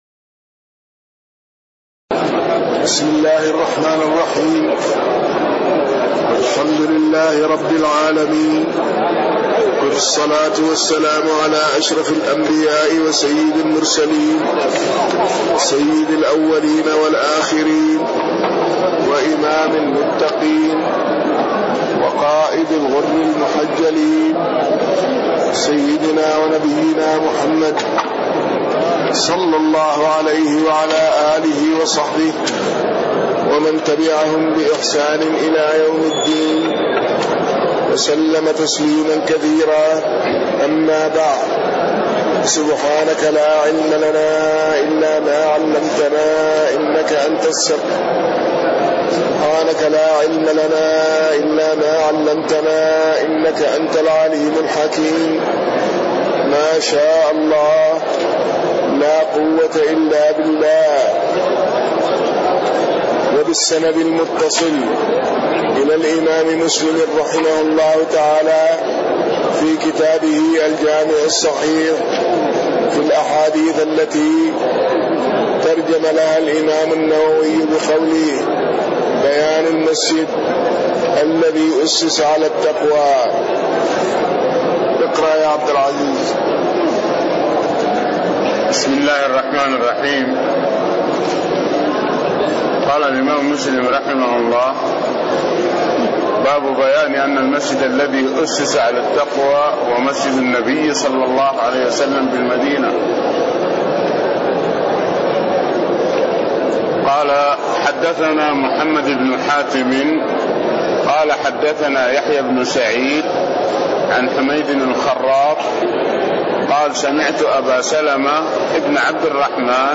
تاريخ النشر ١٩ جمادى الأولى ١٤٣٤ هـ المكان: المسجد النبوي الشيخ